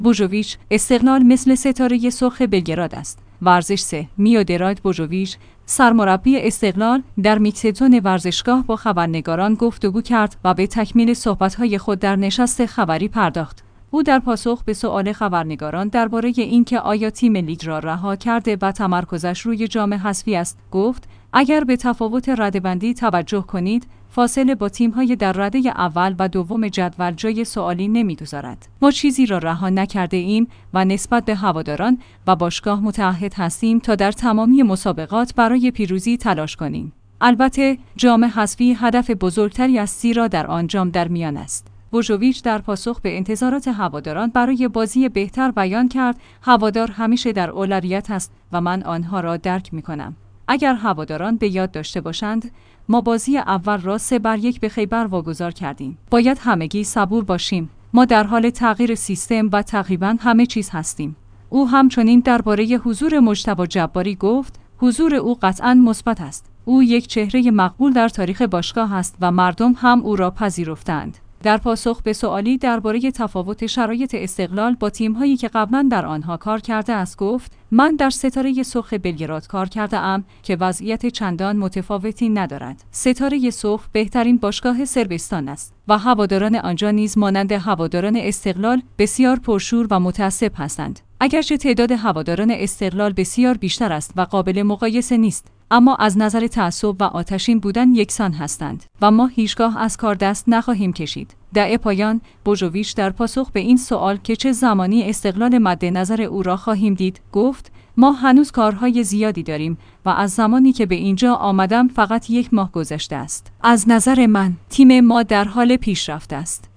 ورزش 3/میودراگ بوژوویچ، سرمربی استقلال، در میکسدزون ورزشگاه با خبرنگاران گفتگو کرد و به تکمیل صحبت‌های خود در نشست خبری پرداخت.